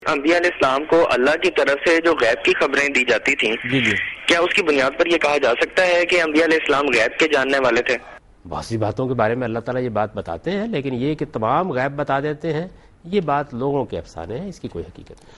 دنیا نیوز کے پروگرام دین و دانش میں جاوید احمد غامدی ”علم غیب“ سے متعلق ایک سوال کا جواب دے رہے ہیں